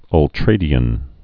(ŭl-trādē-ən)